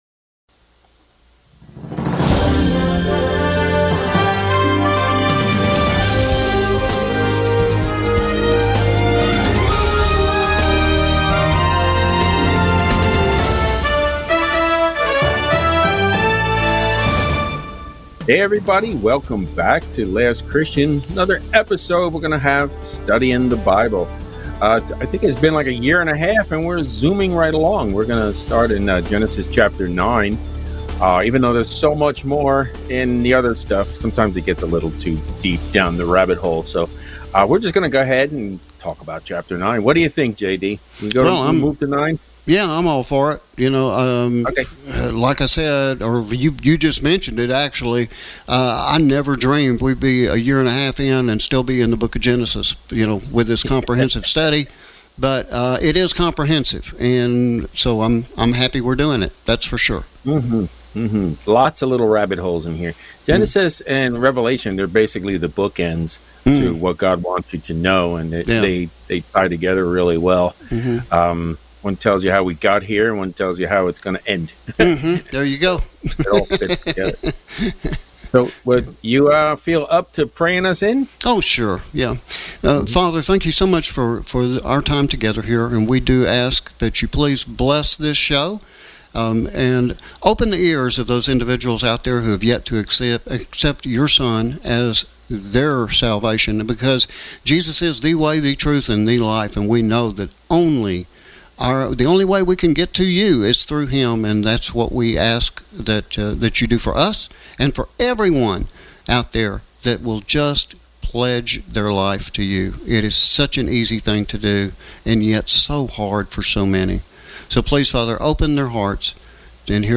Weekly Comprehensive Bible Study Genesis 9 1-3